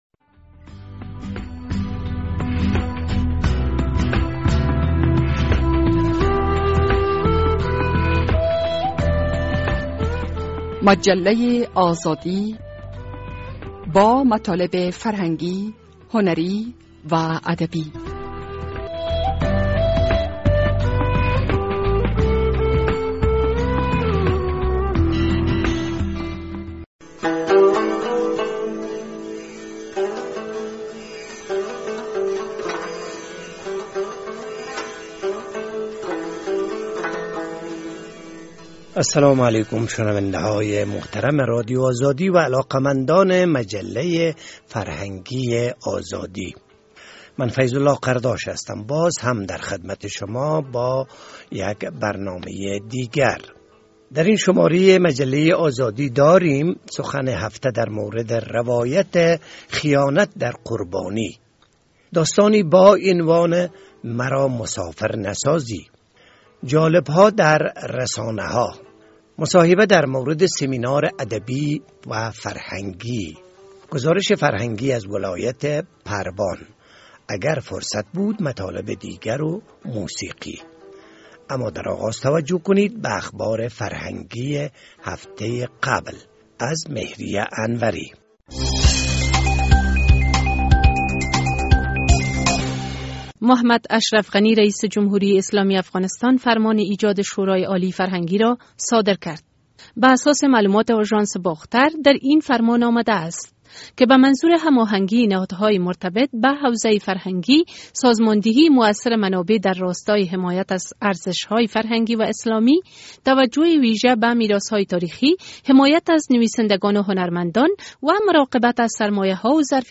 درین شمارۀ مجله آزادی داریم: سخن هفته در مورد روایت خیانت در قربانی، داستانی با عنوان (مرا مسافرنسازی) جالب‌ها در رسانه‌ها، مصاحبه در مورد سیمینار ادبی فرهنگی، گزارش فرهنگی از ولایت پروان.